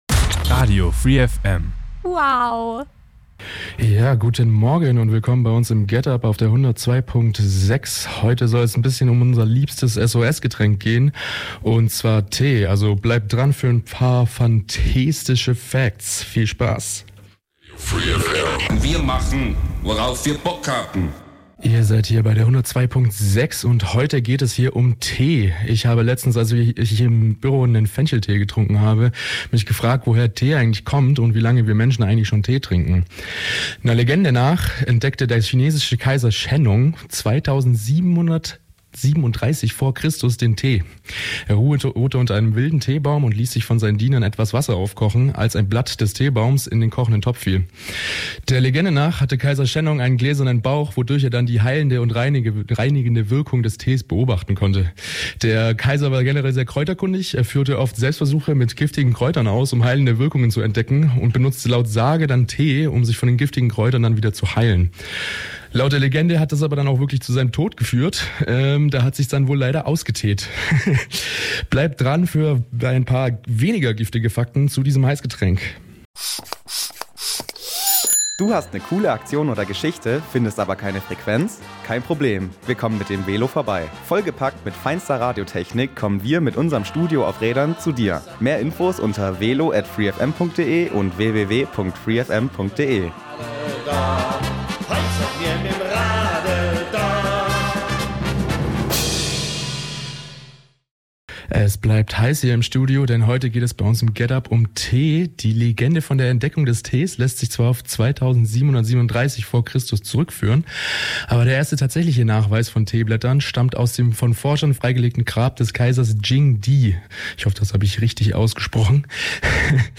Im Rahmen einer spaßigen und wilden Radio-Schnitzeljagd waren wir mit unserem Radio auf Rädern den ganzen Tag in Ulm und Neu-Ulm unterwegs. Wunderschöne Radiomomente live und direkt von der Straße, tolle Begegnungen, kalte Nasen, überraschende Sonnenstrahlen und herausfordernde Verkehrssituationen.